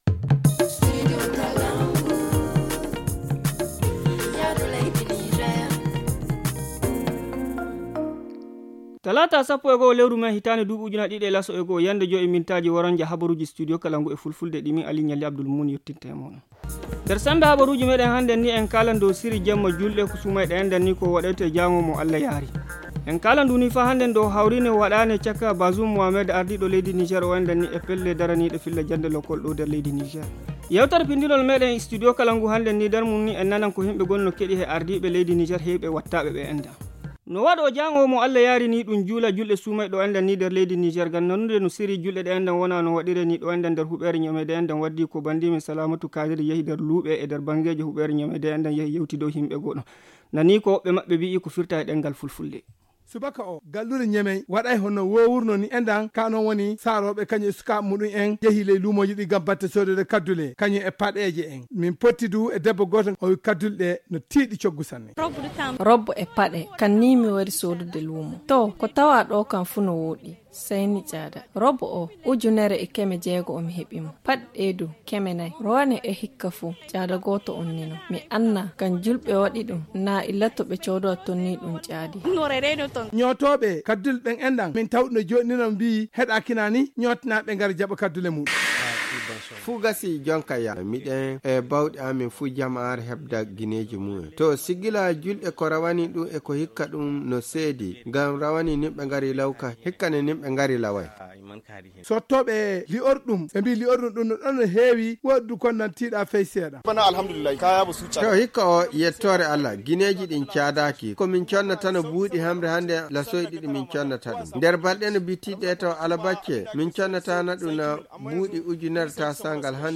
Le journal du 11 mai 2021 - Studio Kalangou - Au rythme du Niger